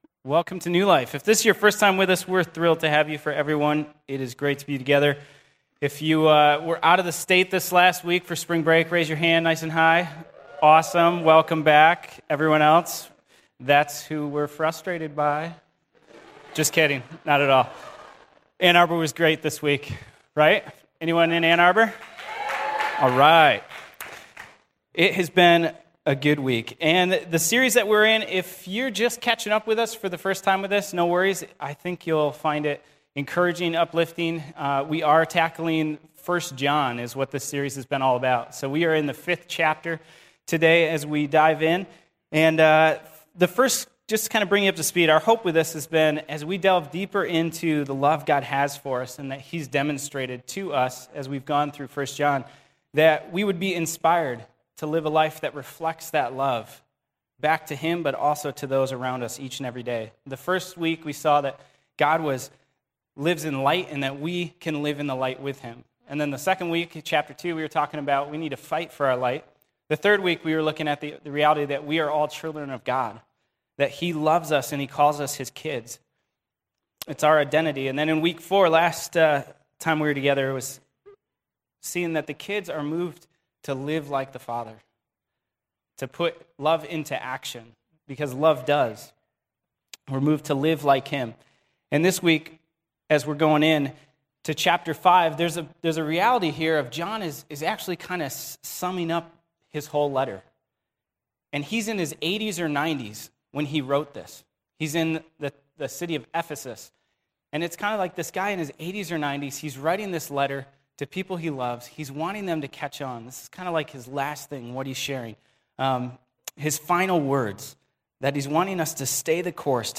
Audio Sermon Save Audio https